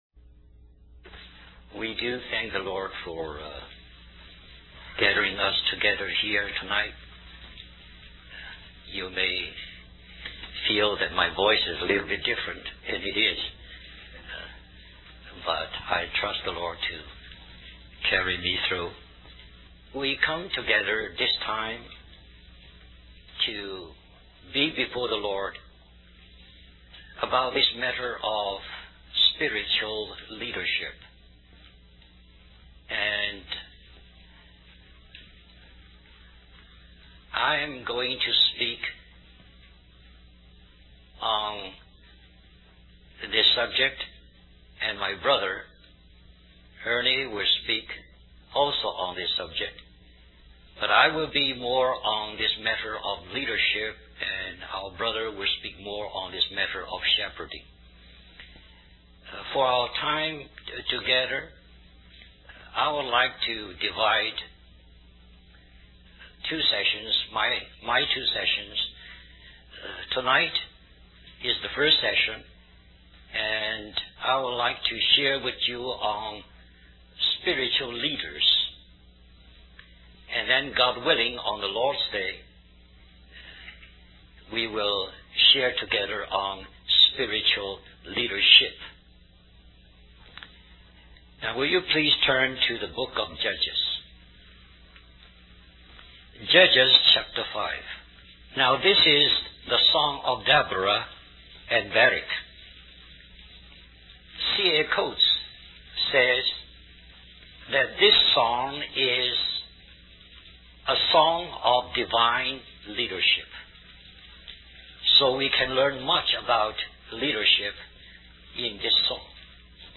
US Stream or download mp3 Summary This message was delivered at the leadership training retreat held in Richmond, Virginia in 1987.